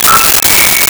Megaphone Feedback 04
Megaphone Feedback 04.wav